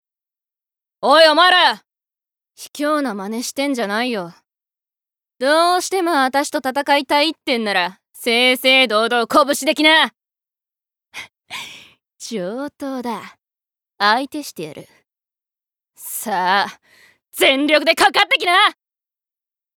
Voice Sample
セリフ１